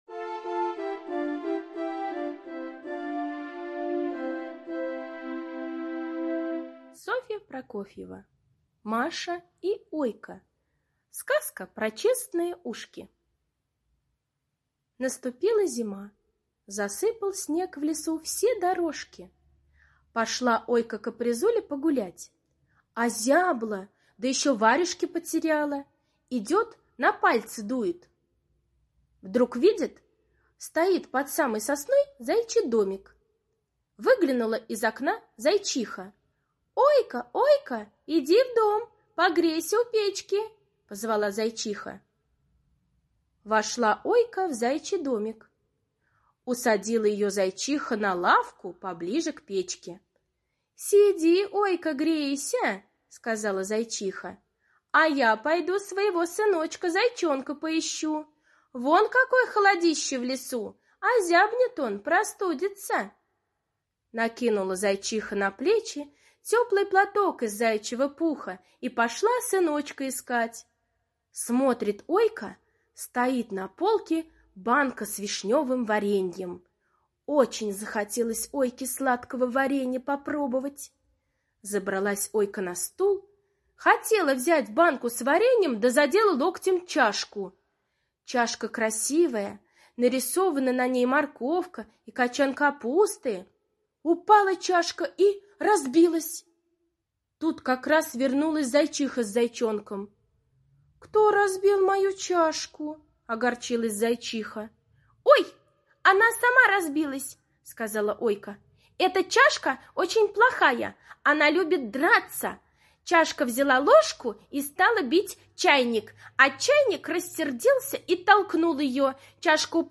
Сказка про честные ушки - аудиосказка Прокофьевой С. Сказка том, как Ойка обманула Зайчиху, когда в ее доме разбила чашку.